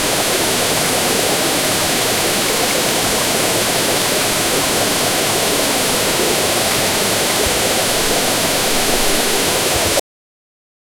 A waterfall roaring as it plunges into a pool of water. 0:11 Created Sep 5, 2024 11:34 PM forest with a river and animals sounds 0:15 Created Oct 12, 2024 12:58 AM A babbling brook flowing through a peaceful meadow. 0:11 Created Sep 5, 2024 11:21 PM
a-waterfall-roaring-as-it-plunges-into-a-pool-of-water-pxf25vyd.wav